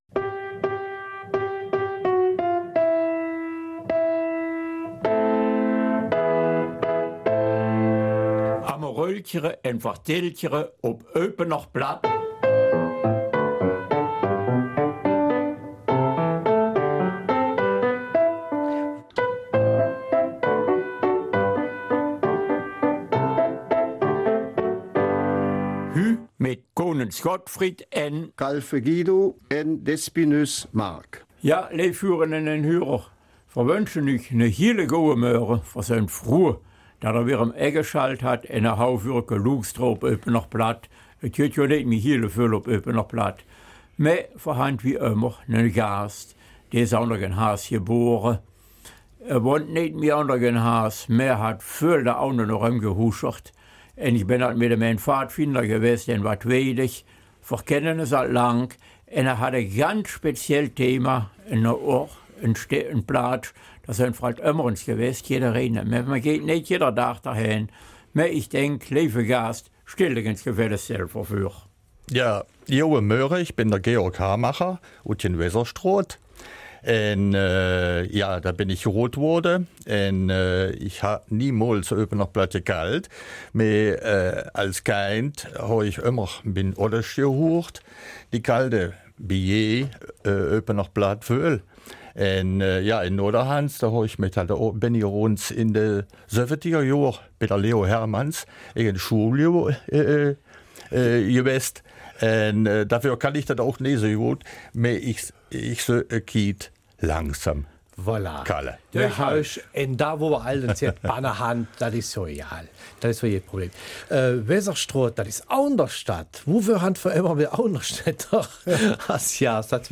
Eupener Mundart: Menschen und ihre Geschichte lassen mich nicht los